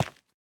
sounds / block / tuff / step5.ogg
step5.ogg